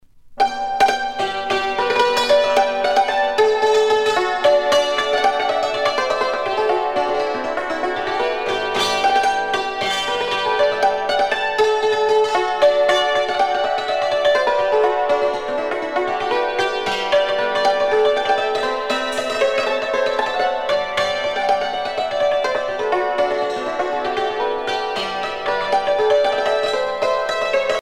Step dance Yarmouth breakdown
Pièce musicale éditée